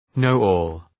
Προφορά
{‘nəʋɔ:l}